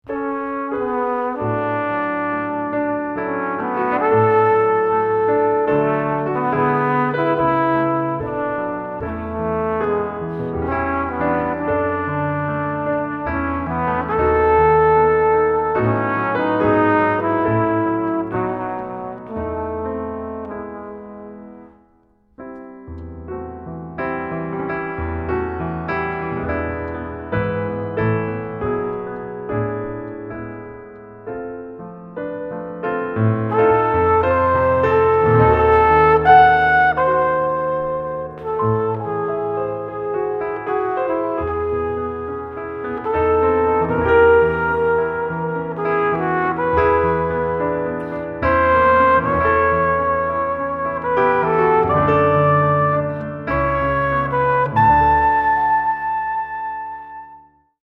flute -
flugelhorn - violin - cello